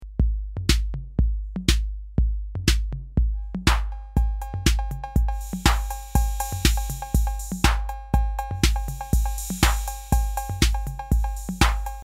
Roland TR-808